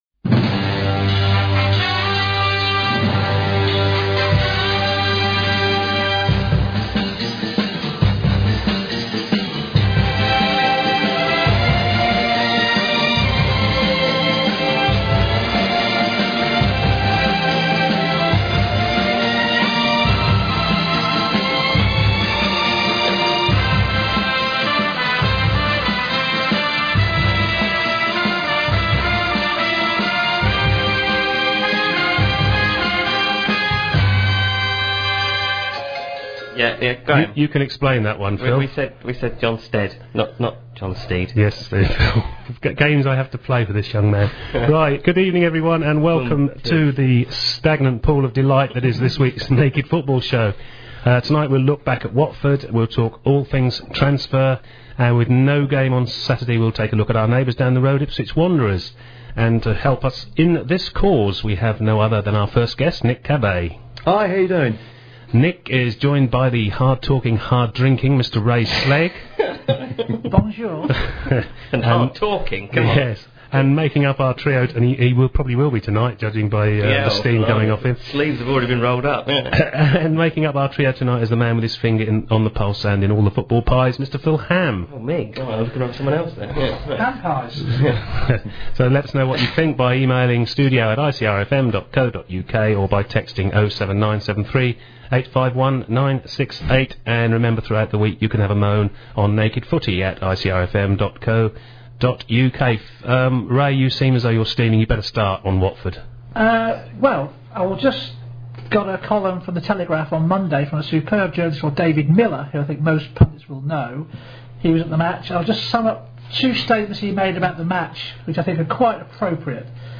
The programme goes out live at 6pm every Wednesday on Ipswich Community Radio at 105.7FM if in the Ipswich area or online if not.